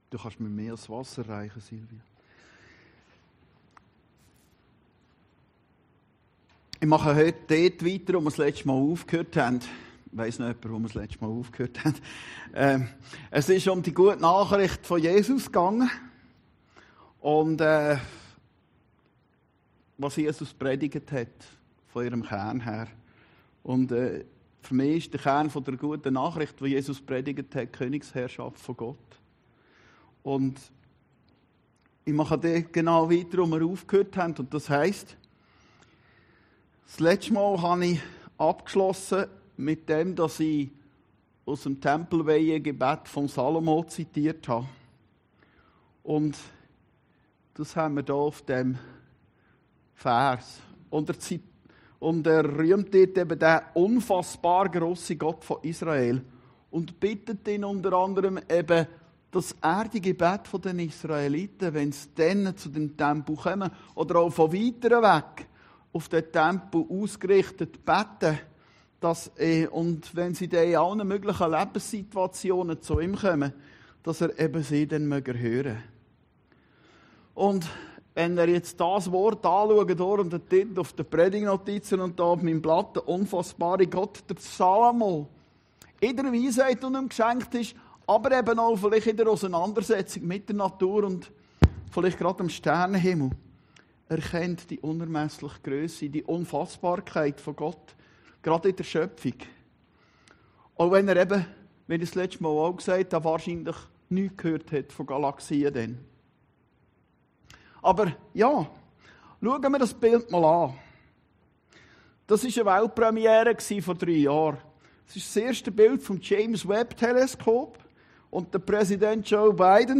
Predigten Heilsarmee Aargau Süd – Unfassbarer Gott